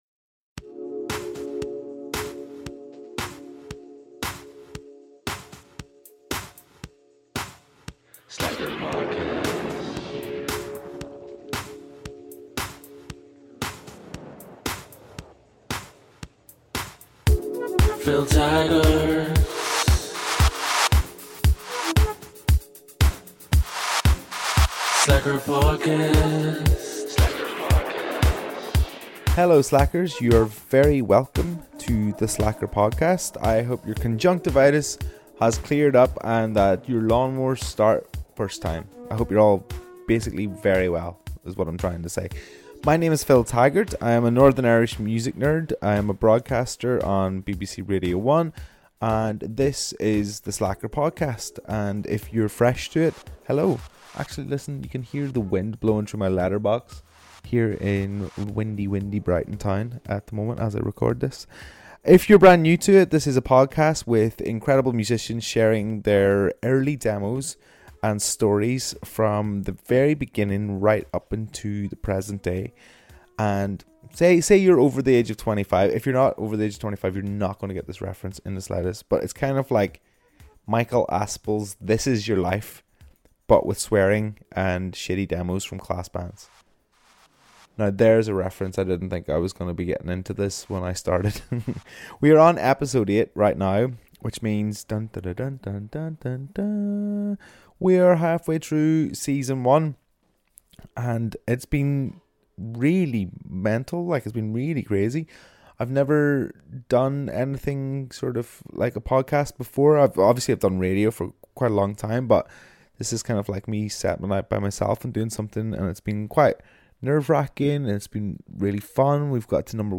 On this podcast we play class artists’ early demos and we get a good chin wag in along the way.